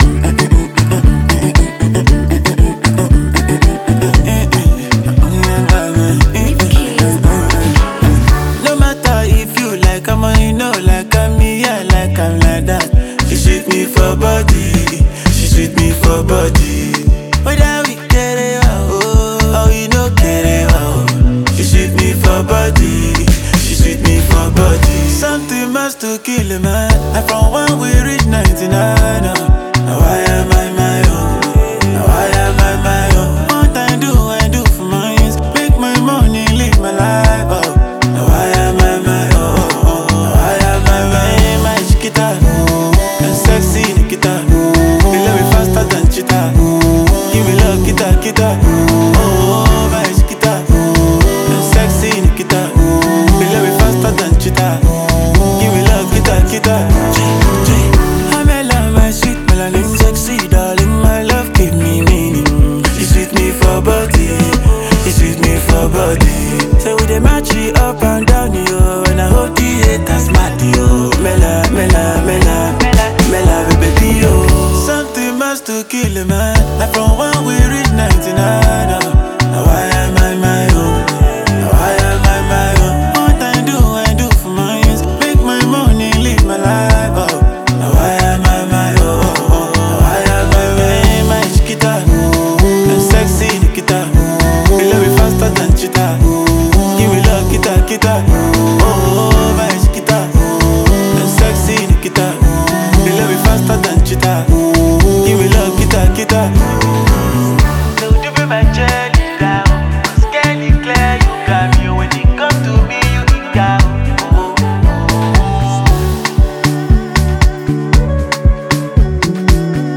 Renowned Nigerian Afrobeats talent and performer
It’s a song packed with depth, melody, and pure creativity.